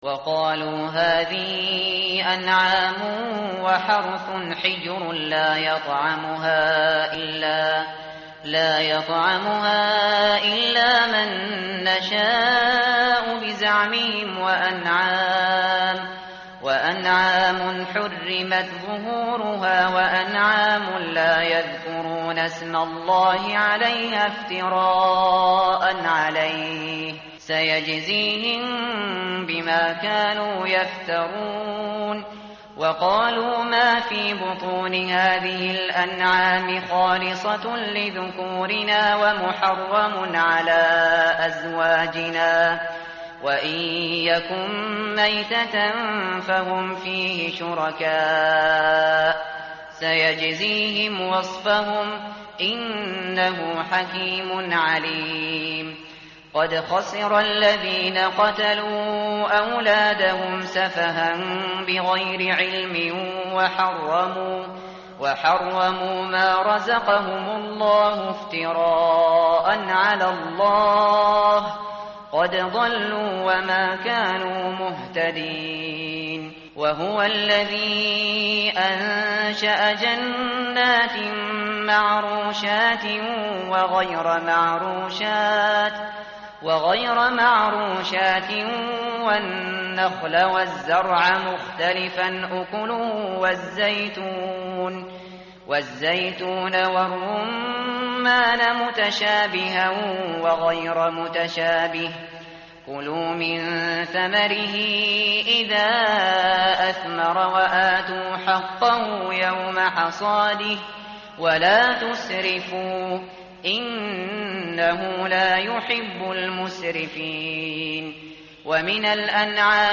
متن قرآن همراه باتلاوت قرآن و ترجمه
tartil_shateri_page_146.mp3